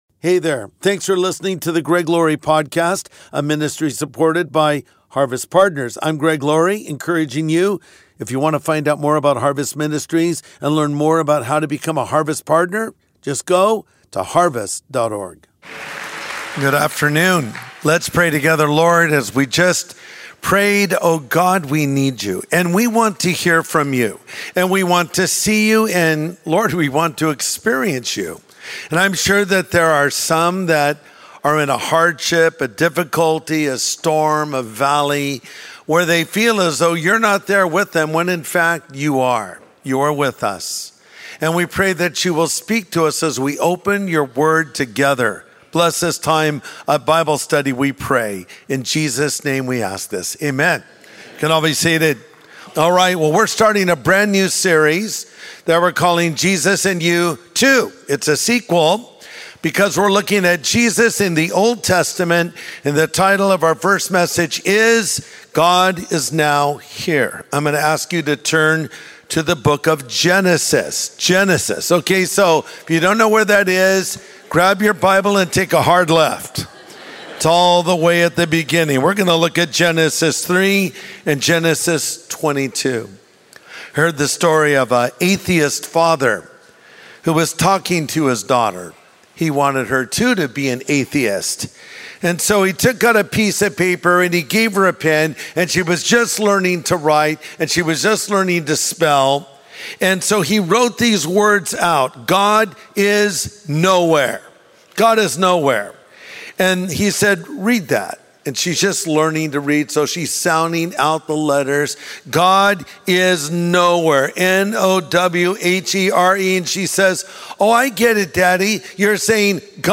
God Is Now Here | Sunday Message
Pastor Greg Laurie shares more in this message.